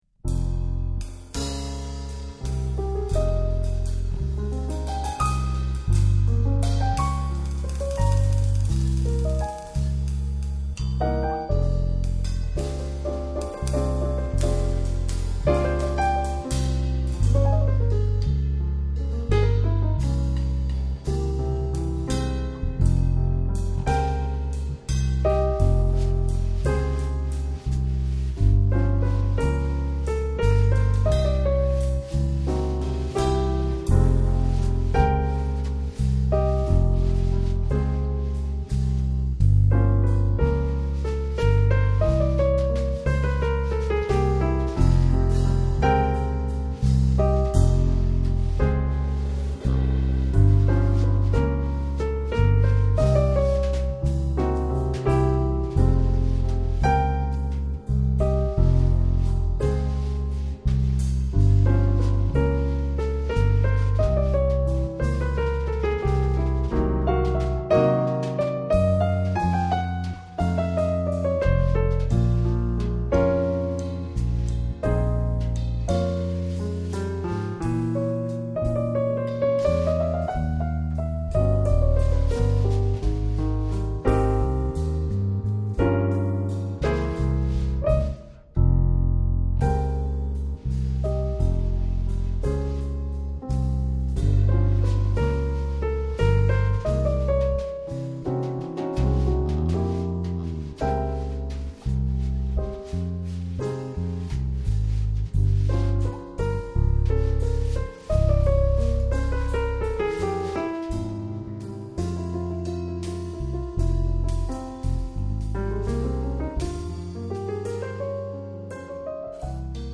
an enchanting mix of jazz, classical, and Brazilian rhythms